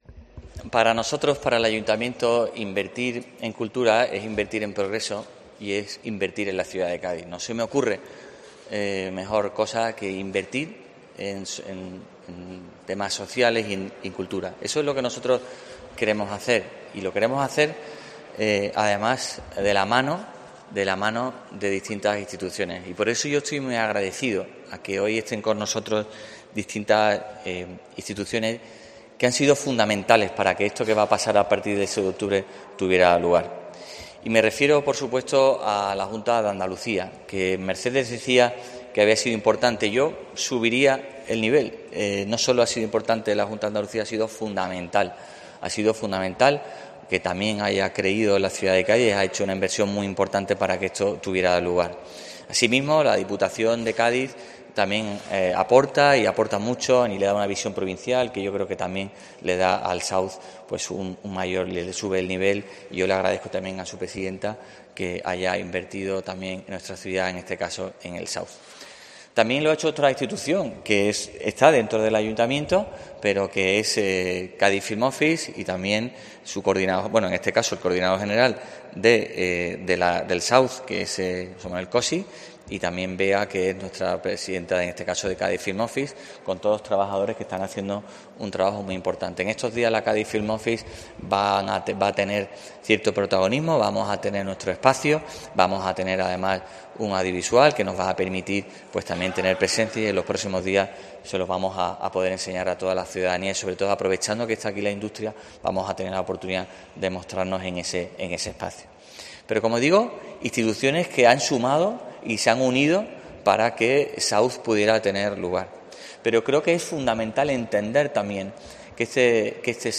Bruno García, alcalde de Cádiz, habla del South International Series Festival
El alcalde de Cádiz, Bruno garcía, habla de lo importante que es y lo que supone para Cádiz, el desarrollo de este festival en la ciudad.